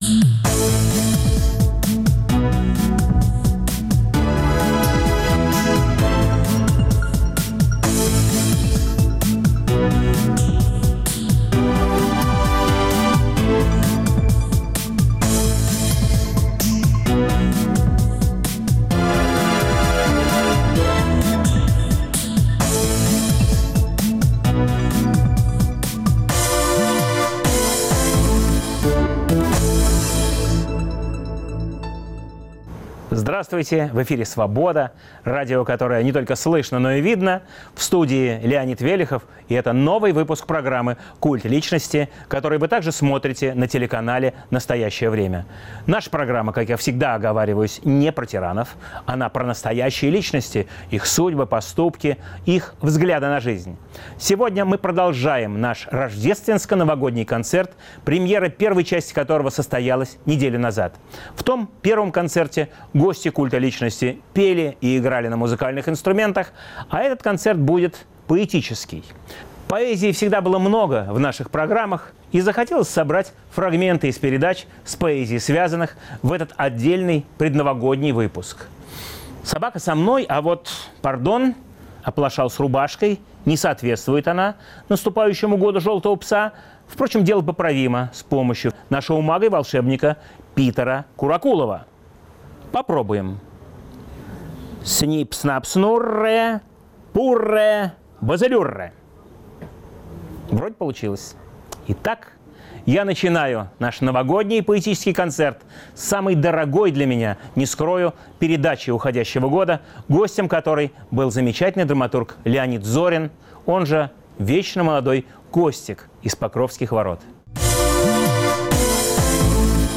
В этом Рождественско-Новогоднем концерте в исполнении гостей «Культа личности» звучат стихи Л. Зорина, А. Гельмана, Б.Пастернака, О.Мандельштама, Е.Евтушенко, Л. Лосева, И.Иртеньева.